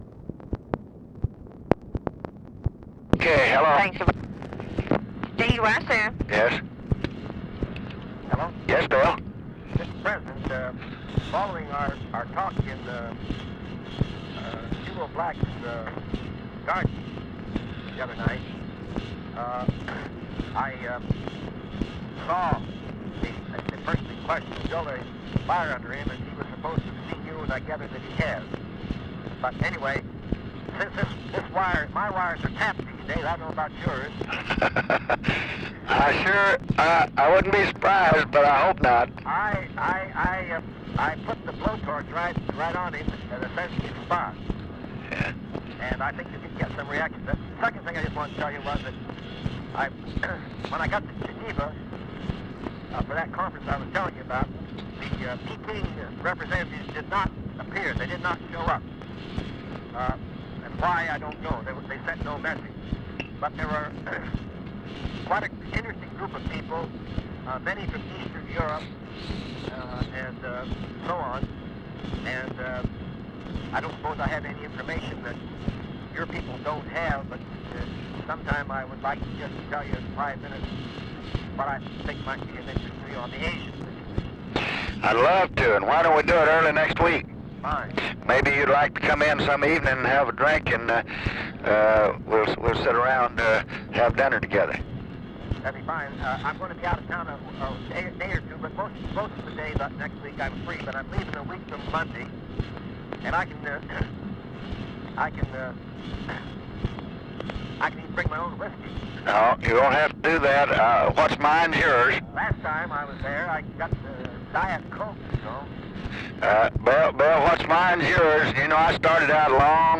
Conversation with (possibly) WILLIAM O. DOUGLAS, June 4, 1966
Secret White House Tapes